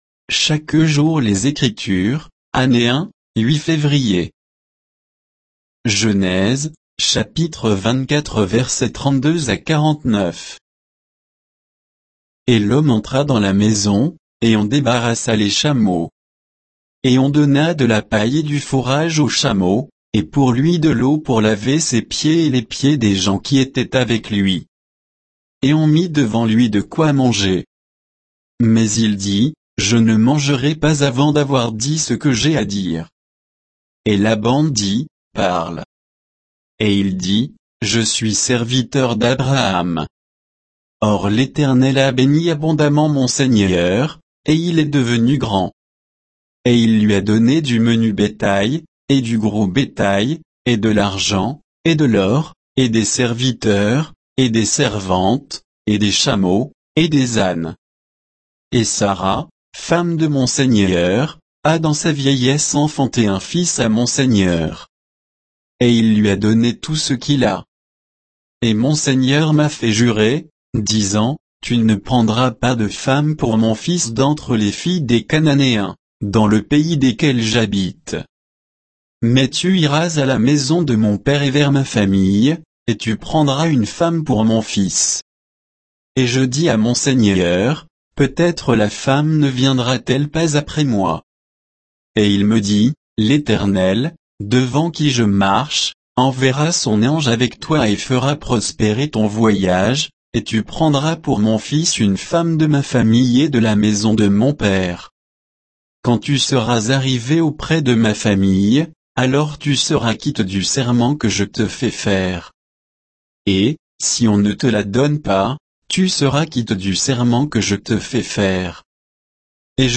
Méditation quoditienne de Chaque jour les Écritures sur Genèse 24